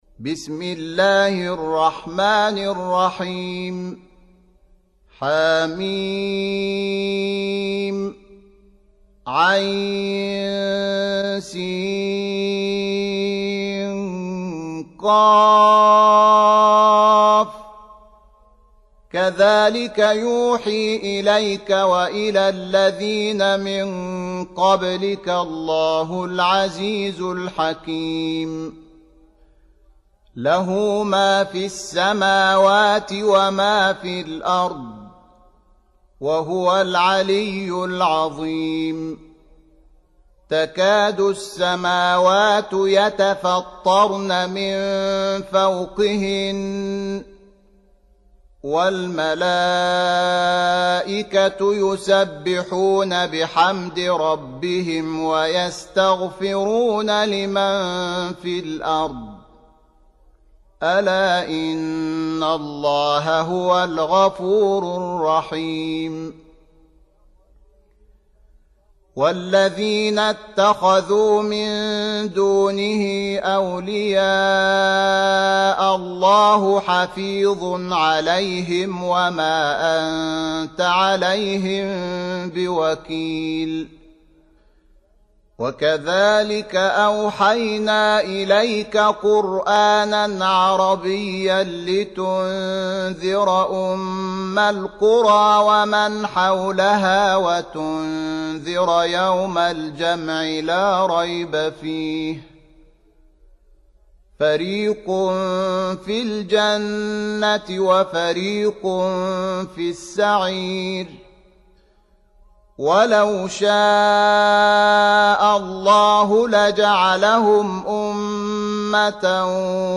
سورة الشورى - الشحات محمد أنور (صوت) - جودة عالية. التصنيف: تلاوات مرتلة